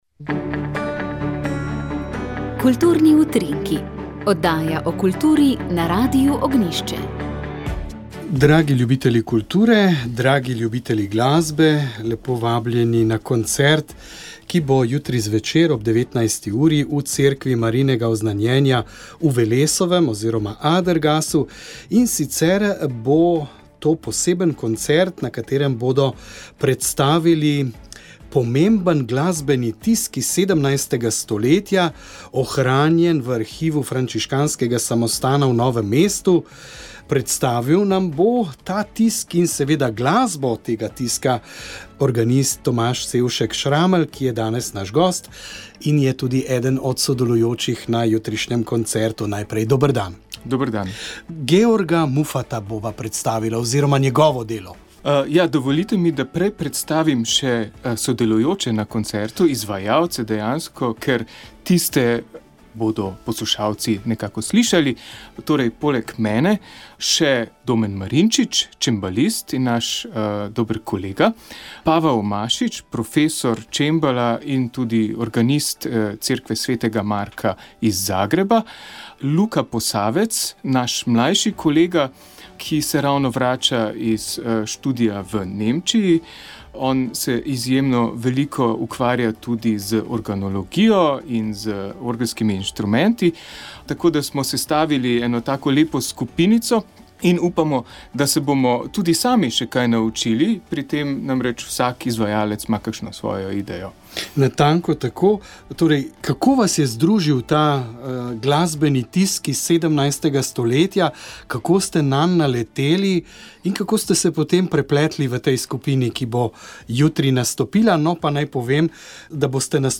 V naši otroški oddaji smo se ustavili pri lepoti slovenske besede in pesmi, slišali ste slovensko ljudsko pripoved o prevarani lisici.